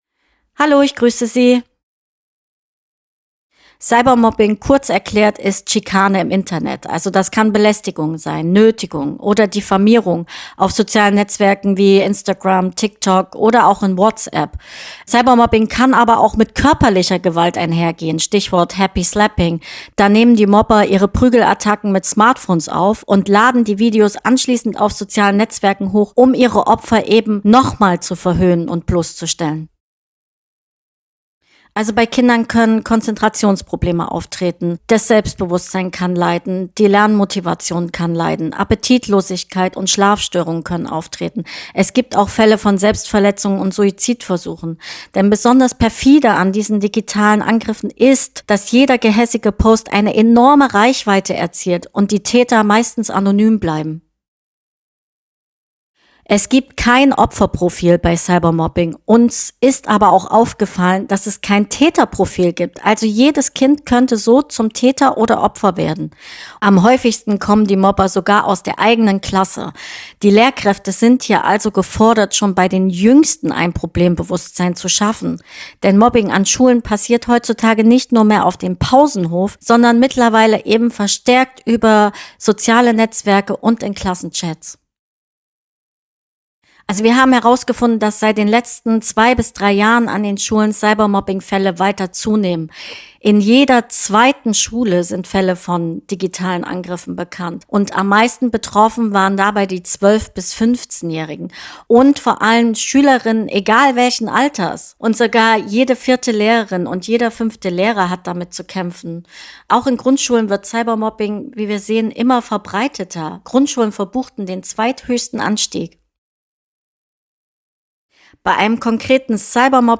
Interview: Cybermobbing: Cybermobber lauern schon in Grundschulen.
interview-cybermobbing.html